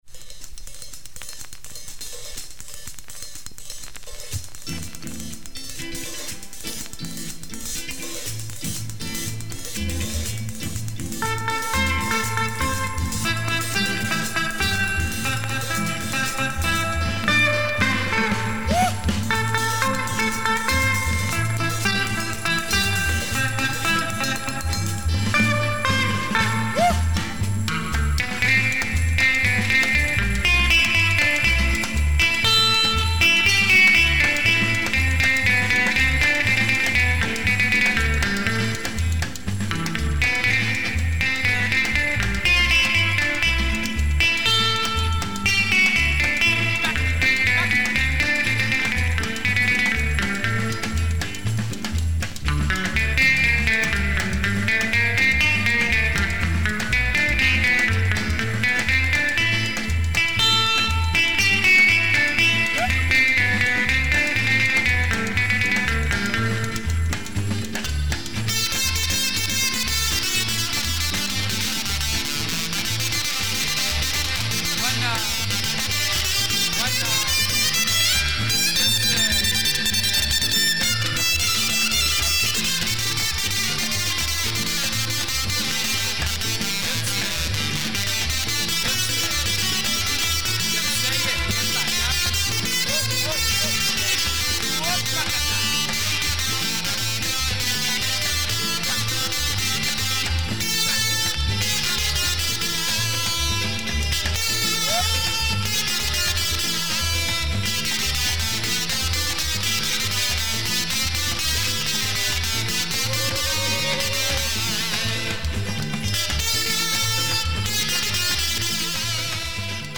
Dope Haitian album
Soulful compas with psych guitar or Caribbean latin jazz